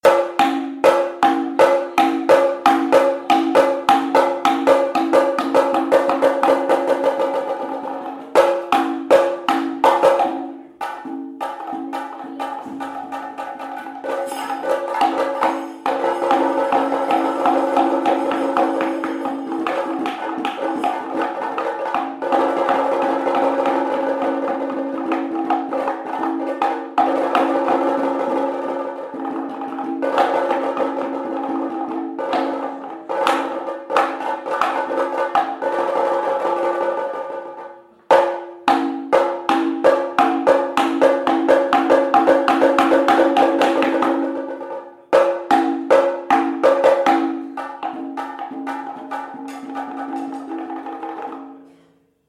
keraladrums2.mp3